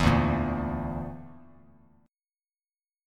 Dsus2#5 chord